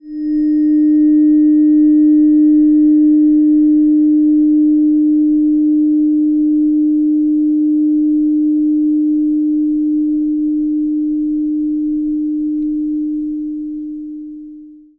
Fork High Chiron
fork_High-Chiron.mp3